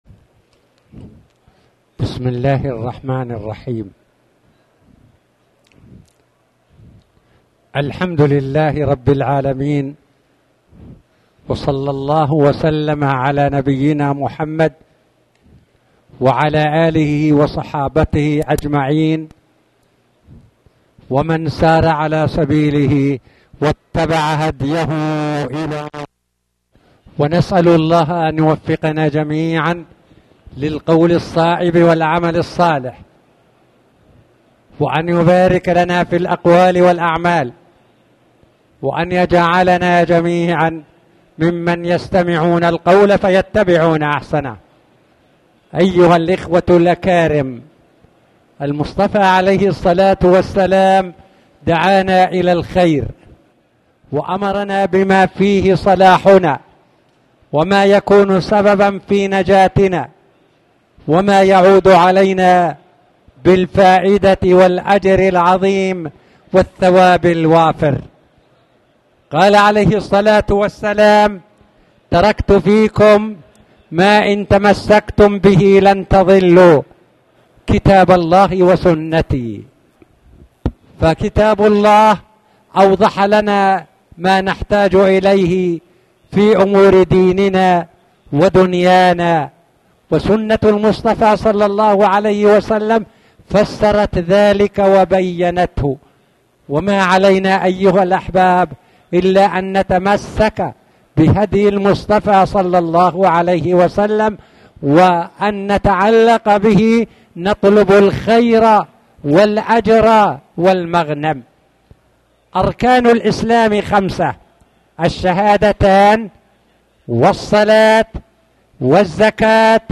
تاريخ النشر ٨ محرم ١٤٣٩ هـ المكان: المسجد الحرام الشيخ